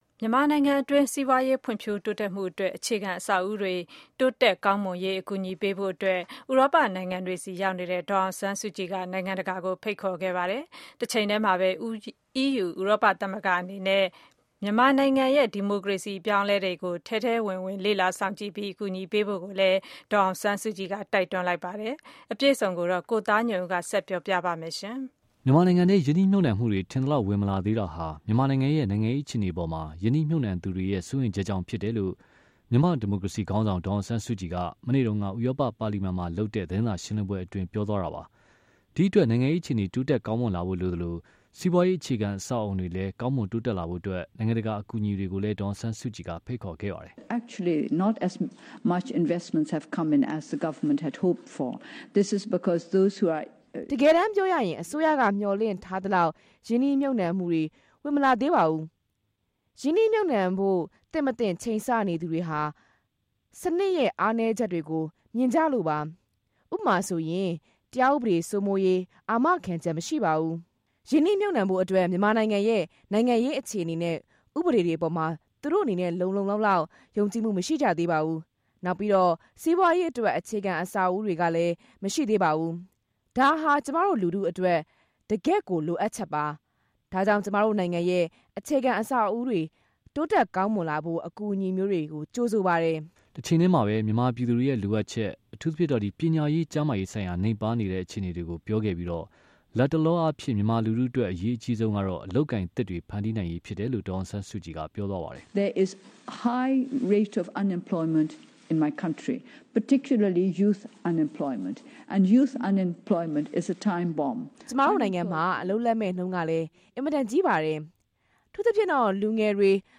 ဒေါ်အောင်ဆန်းစုကြည်ရဲ့ သတင်းစာရှင်းလင်းပွဲ